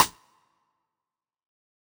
Snare (SizzleLife2).wav